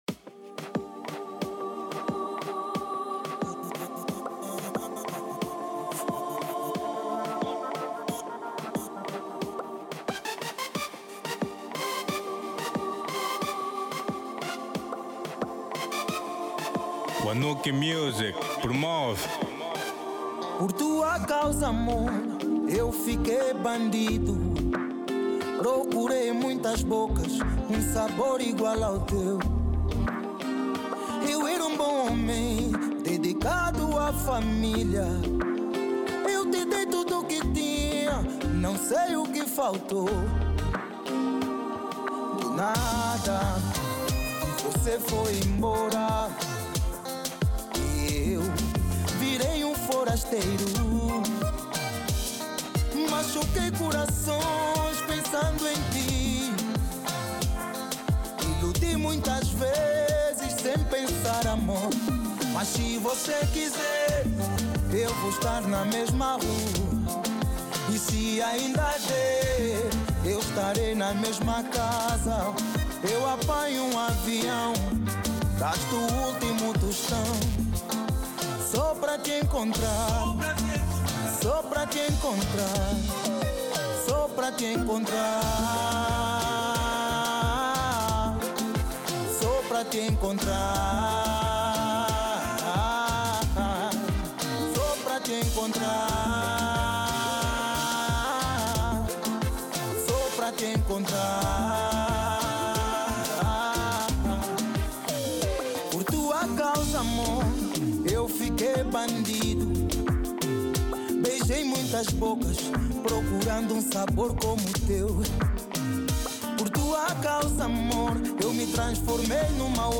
Genero: Semba